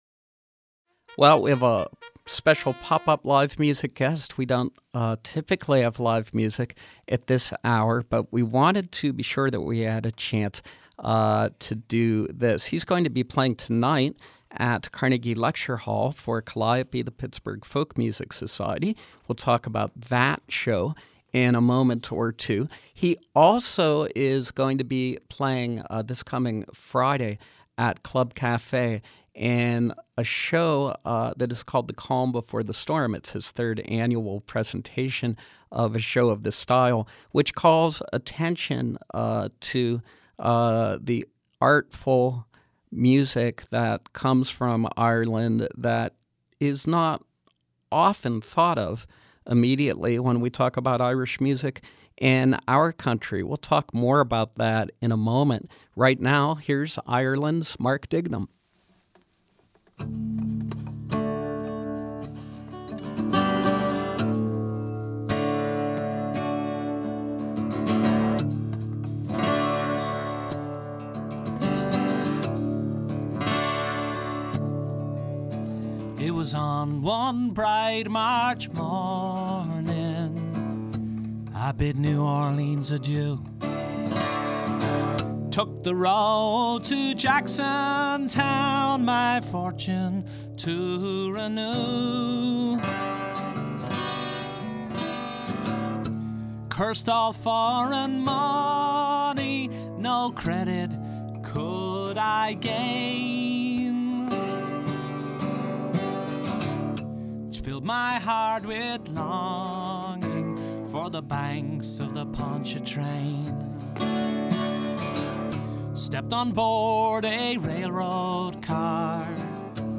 Irish born singer/songwriter